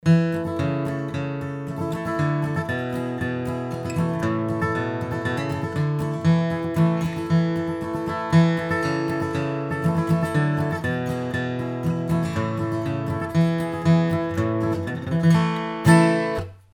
Capo am 2ten Bund, glaube ich mich dumpf zu erinnern, Akkordformen G udd D also in Wirklichkeit A und E.
flatpicking_melody_haenschen.mp3